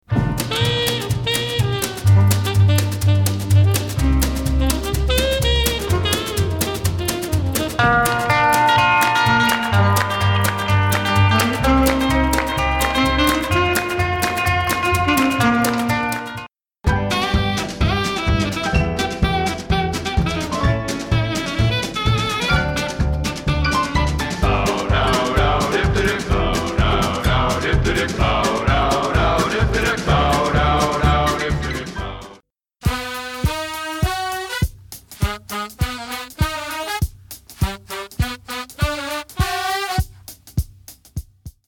a punk sensibility and a doo wop arrangement
sax